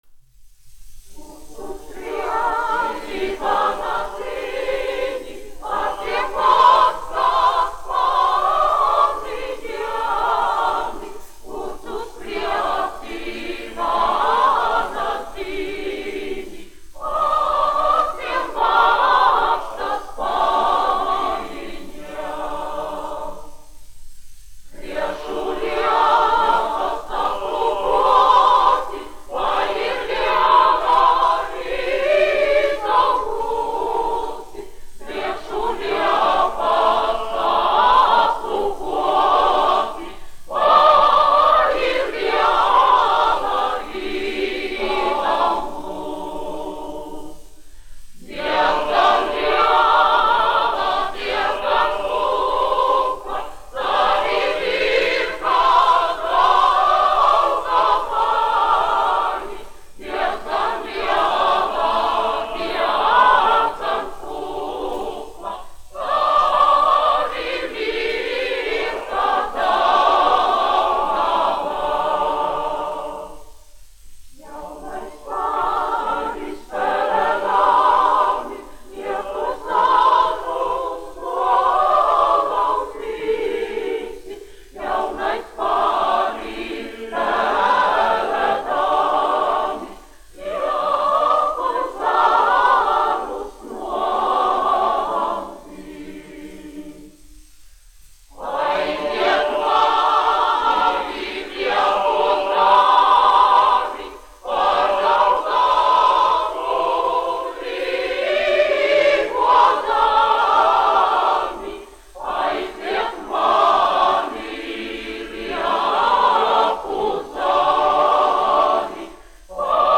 Andrejs Jurjāns, 1856-1922, aranžētājs
Valsts Akadēmiskais koris "Latvija", izpildītājs
1 skpl. : analogs, 78 apgr/min, mono ; 25 cm
Latviešu tautasdziesmas
Kori (jauktie)
Skaņuplate